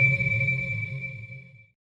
sonarTailWaterMedium1.ogg